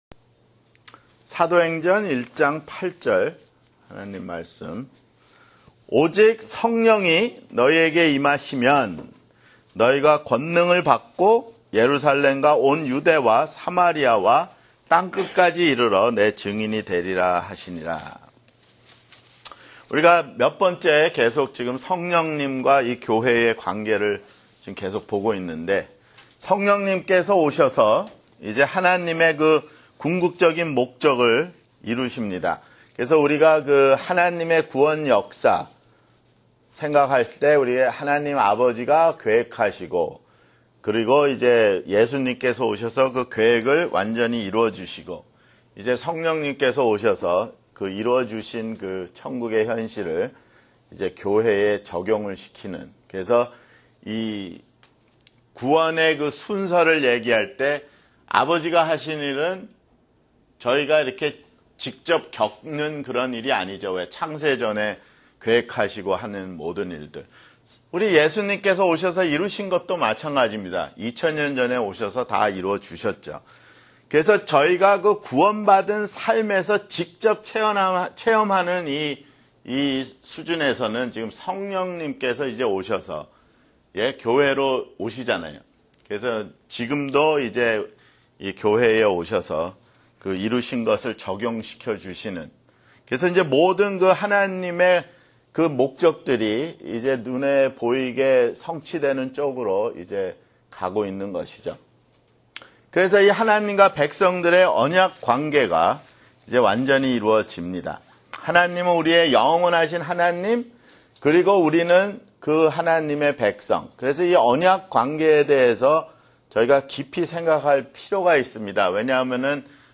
[성경공부] 교회 (10)